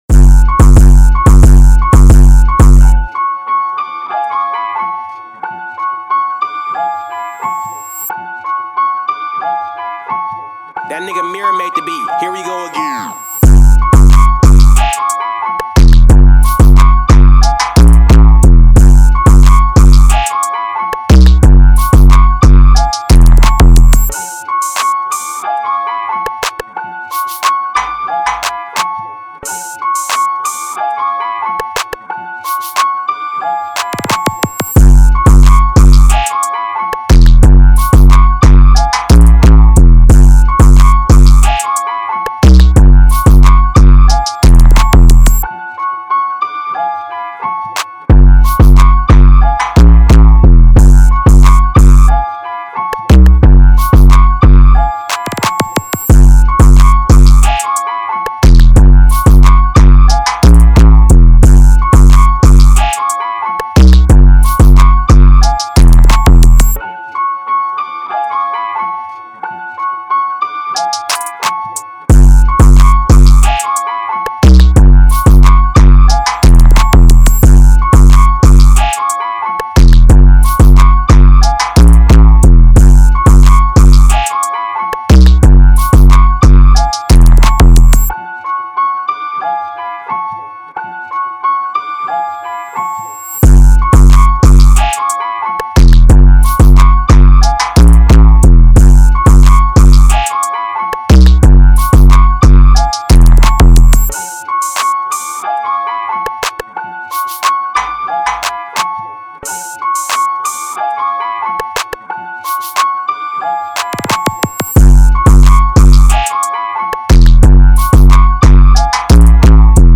2024 in Official Instrumentals , Rap Instrumentals